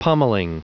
Prononciation du mot pummelling en anglais (fichier audio)
Prononciation du mot : pummelling
pummelling.wav